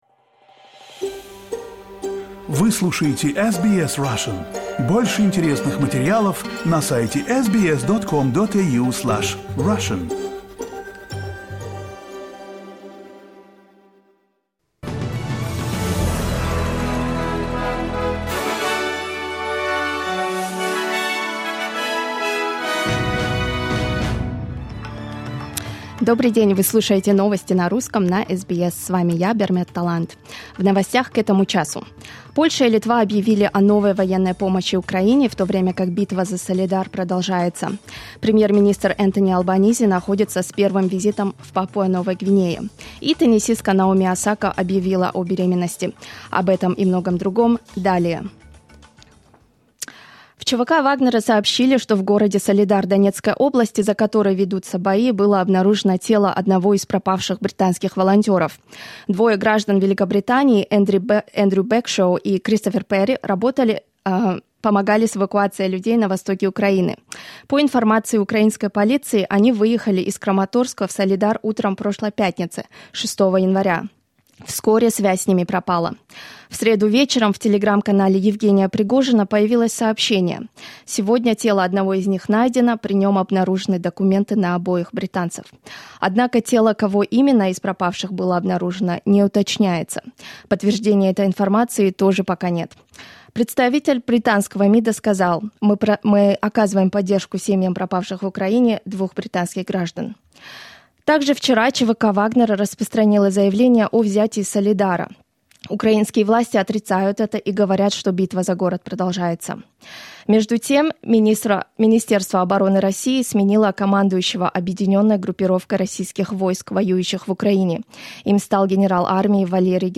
SBS news in Russian — 12.01.2023